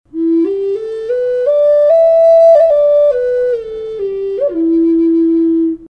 Пимак E Тональность: E
Модель изготовлена из древесины ясеня.
Пимак или "флейта любви" является национальным духовым инструментом североамериканских индейцев.